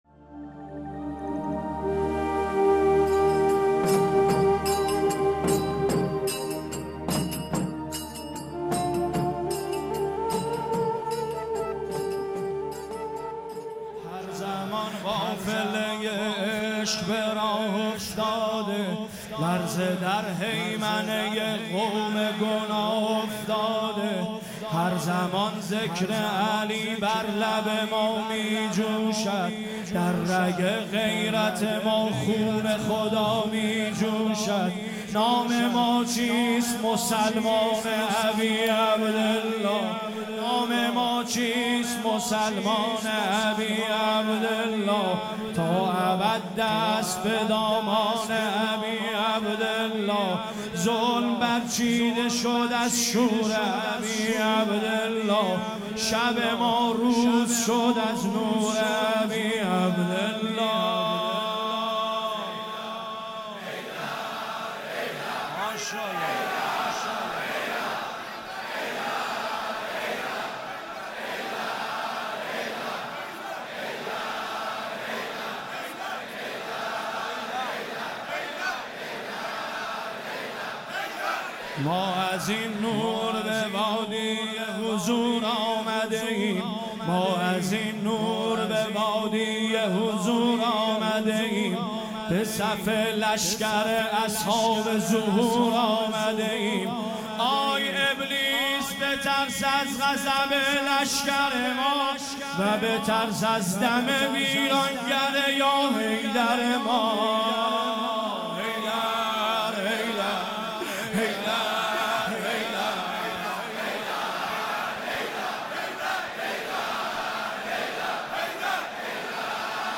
مدح | تا ابد دست به دامن ابی عبدالله
مدح خوانی
شب نهم محرم 1401